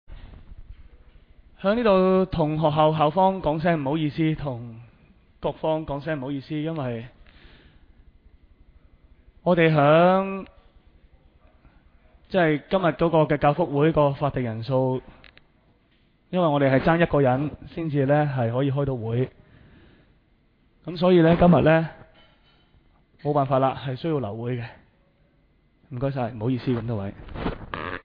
委员会会议的录音记录
教育及福利委员会第二次 (因法定人数不足，会议未能召开)会议 日期: 2021-07-08 (星期四) 时间: 下午2时30分 地点: 沙田民政事务处441会议室 议程 讨论时间 I 因法定人数不足而休会 00:00:28 全部展开 全部收回 议程:I 因法定人数不足而休会 讨论时间: 00:00:28 前一页 返回页首 如欲参阅以上文件所载档案较大的附件或受版权保护的附件，请向 区议会秘书处 或有关版权持有人（按情况）查询。